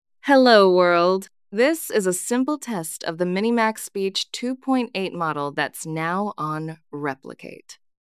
text-to-speech voice-cloning
"channel": "mono",
"emotion": "auto",
"voice_id": "Wise_Woman",